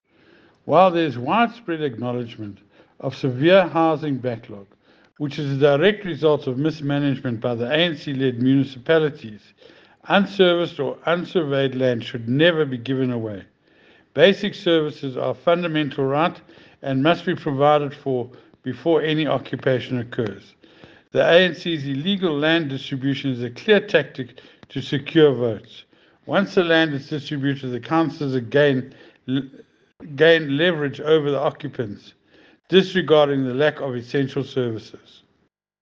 English soundbite by Cllr Ian Riddle and